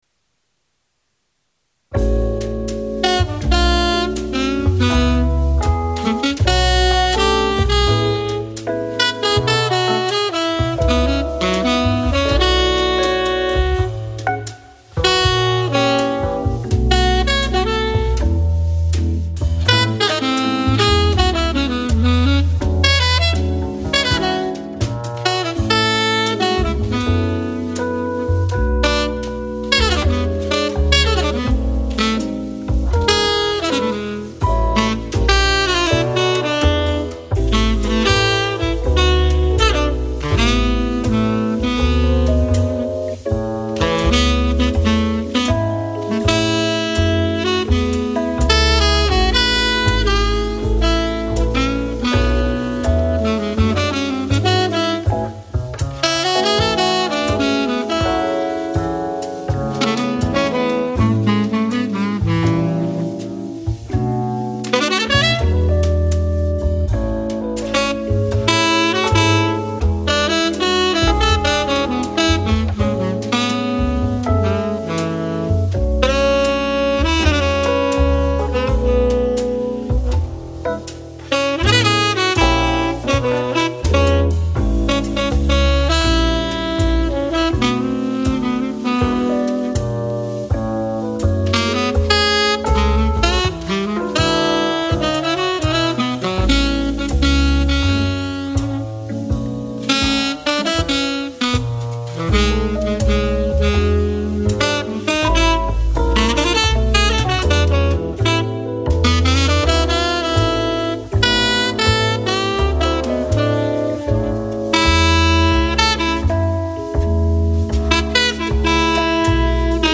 FLAC Жанр: Jazz, Chillout Издание